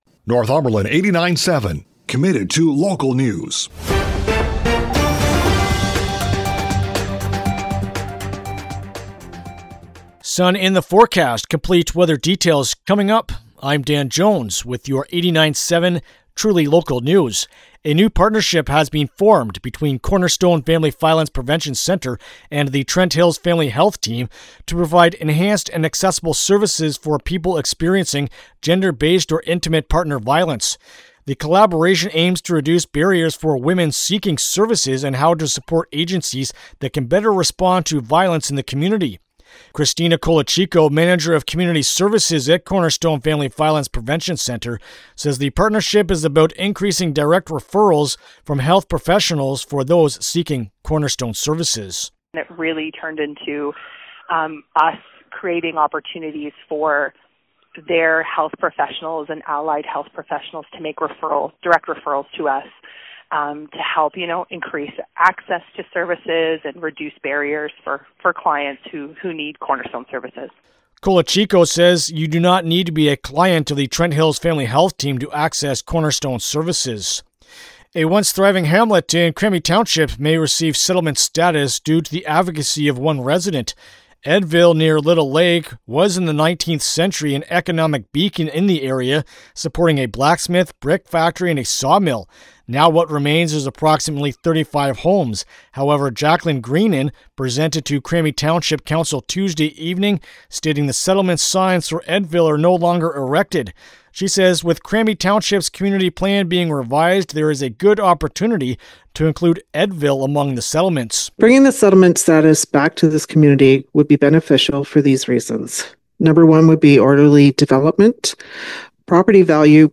CFWN-Cobourg-ON.-Wed.-Jan.-28-Afternoon-News.mp3